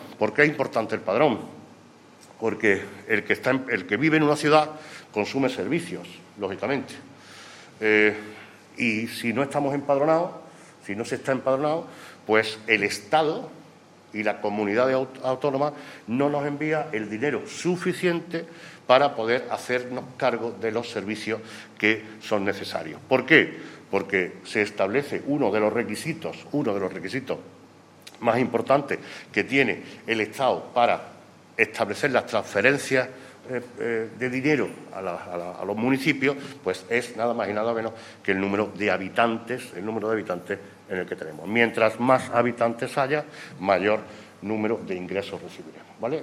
El alcalde de Antequera, Manolo Barón, y el teniente de alcalde delegado de Presidencia y Régimen Interior, Juan Rosas, han informado en rueda de prensa sobre los datos estadísticos del padrón municipal de habitantes de la década que acaba de concluir.
Cortes de voz